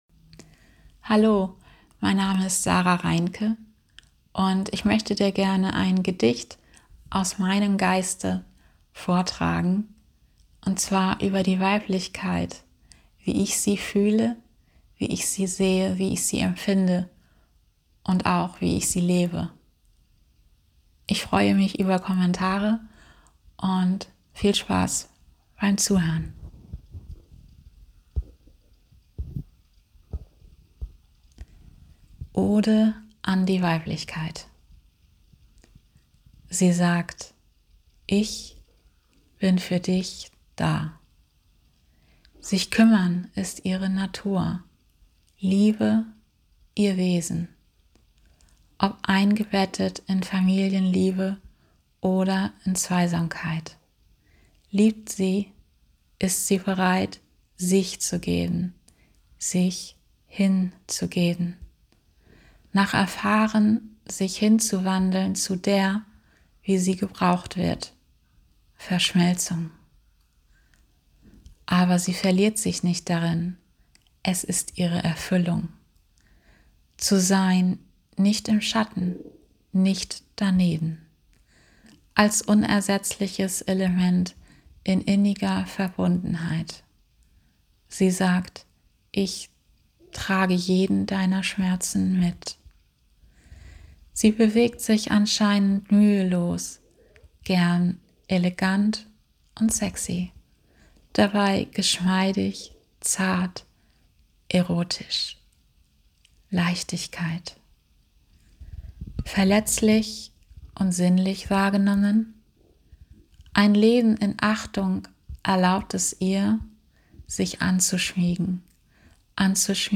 Gedicht